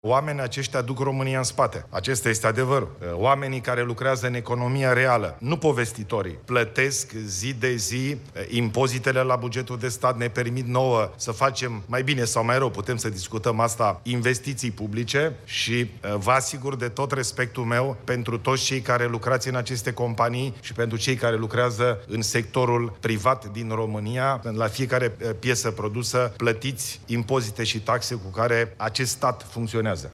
„Oamenii aceștia duc România în spate”, a declarat premierul despre companiile private. Ilie bolojan a mers într-o fabrică din Sebeș, județul Alba, în care se produc componente pentru mașini electrice (mașinile electrice ale Mercedes-Benz), după ce aceasta a trecut printr-un proces de re-tehnologizare.